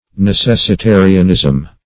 Necessitarianism \Ne*ces`si*ta"ri*an*ism\, n.
necessitarianism.mp3